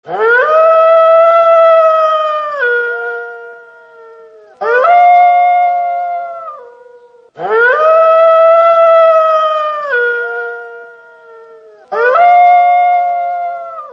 Sonnerie Hurlement De Loup
Catégorie Animaux
Hurlement-de-loup.mp3